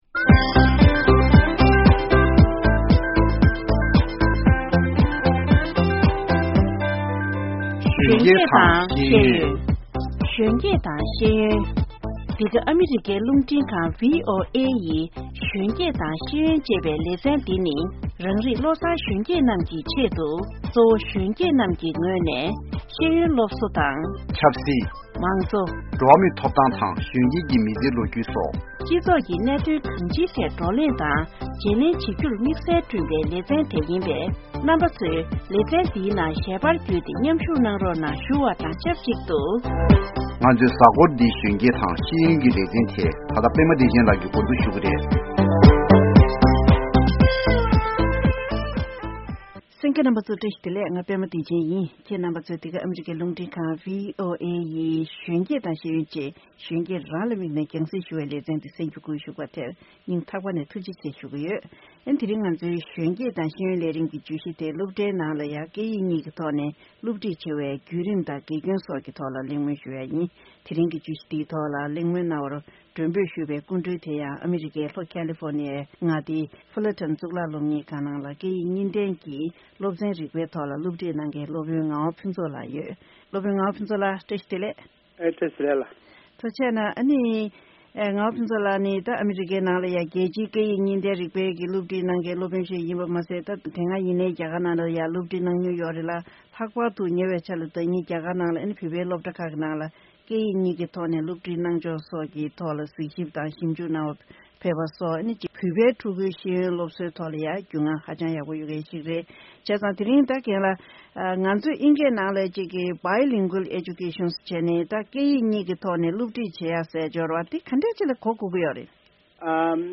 བགྲོ་གླེང་གནང་བ་ཞིག་གསན་གྱི་རེད། དེང་སང་འཛམ་གླིང་གི་ཁྲོད་དུ་སྐད་ཡིག་གཉིས་ཀྱི་ཐོག་ནས་སློབ་ཚན་འདྲ་མིན་སློབ་ཁྲིད་བྱེད་ཤུགས་ཆེ་རུ་འགྲོ་བཞིན་པ་དང་དུས་མཚུངས་སློབ་ཕྲུག་ཚོས་དུས་གཅིག་ལ་སྐད་ཡིག་གཉིས་སློབ་སྦྱོང་བྱེད་རྒྱུར་དཀའ་ངལ་འཕྲད་ཀྱི་ཡོག་མ་རེད་ཅེས།